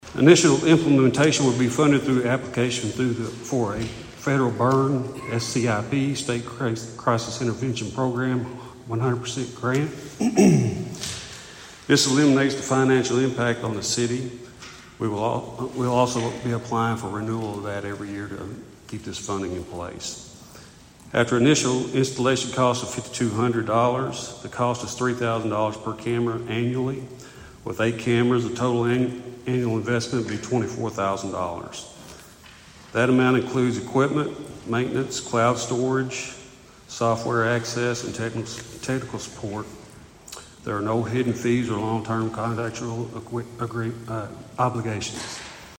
At Monday night’s meeting, the Princeton City Council approved applying for a grant to install automated license plate reader cameras at key city entry points.